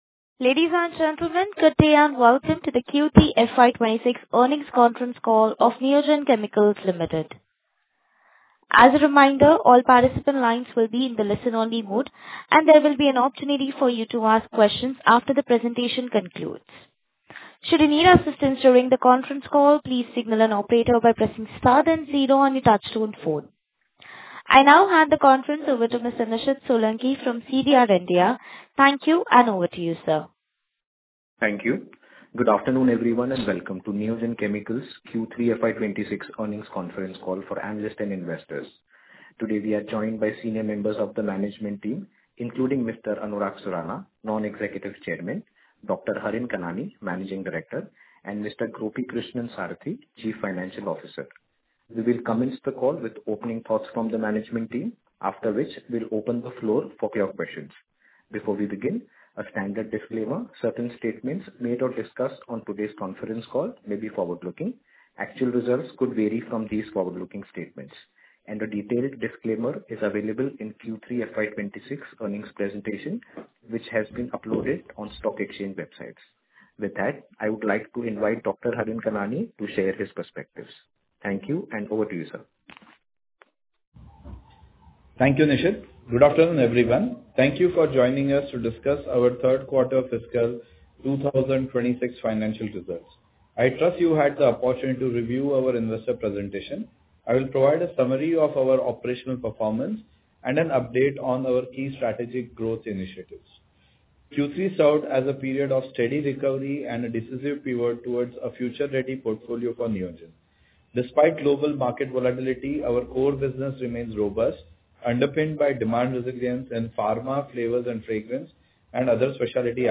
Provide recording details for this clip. The conference call took place today, Thursday, February 12, 2026, commencing at 02:00 p.m.